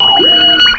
cry_not_elgyem.aif